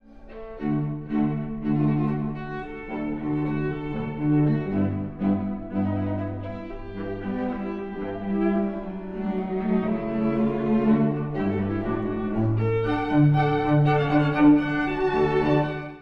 （冒頭）　※古い録音のため聴きづらいかもしれません！
“Vivace assai”…とても生き生きと。
第二主題は、さらにスキップしたくなるような麗らかさです。